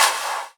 VEC3 Claps 024.wav